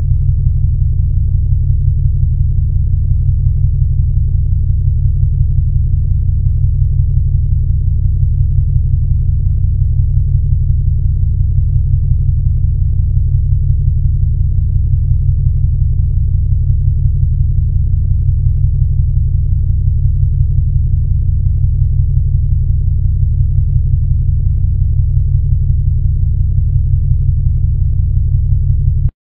ammochamber.ogg